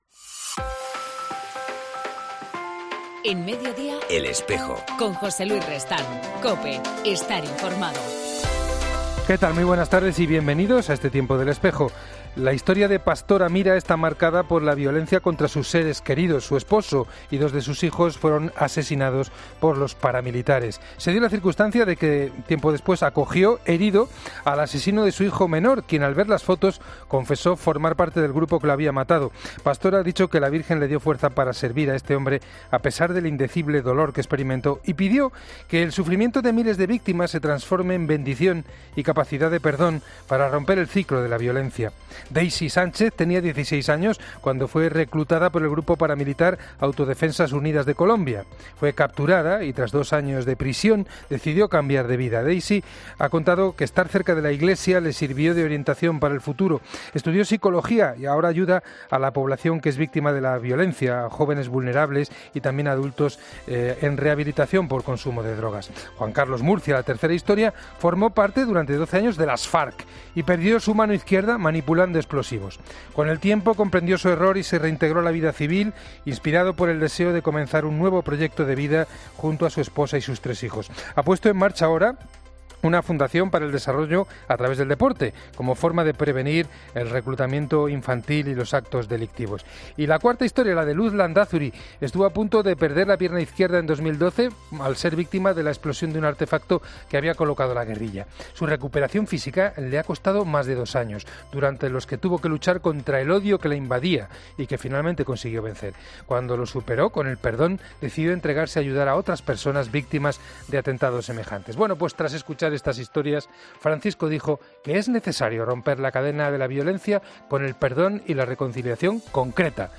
En El Espejo del 11 de septiembre entrevistamos a los nuevos obispos auxiliares de la Archidiócesis de Barcelona